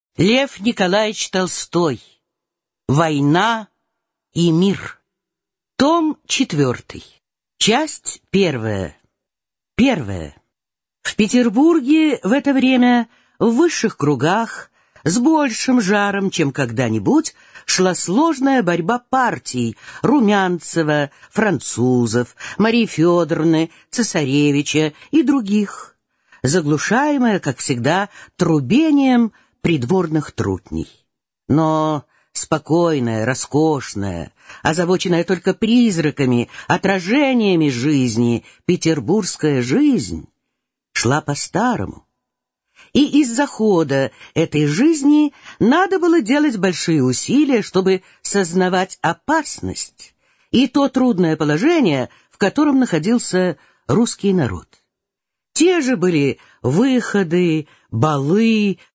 Аудиокнига Война и мир. Том 4 | Библиотека аудиокниг